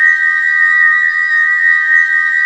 A#4 WHIST03L.wav